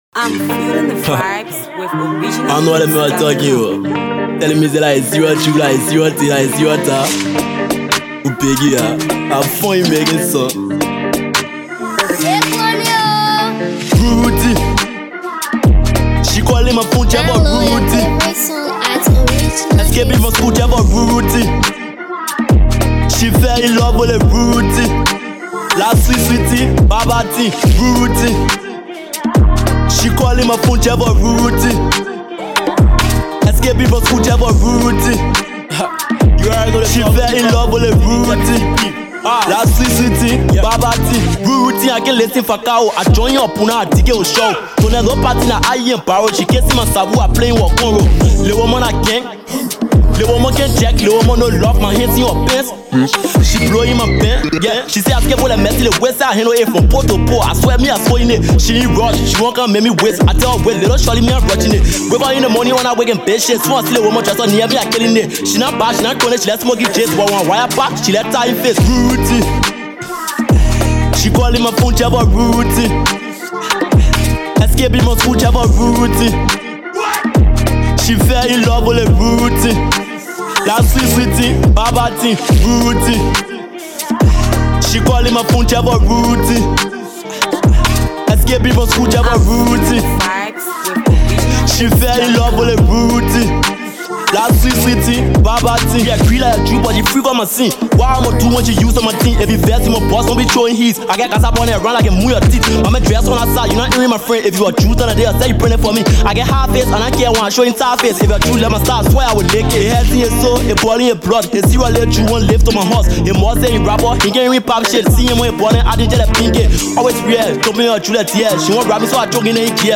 Grab your copy of this Drill/afro beats piece.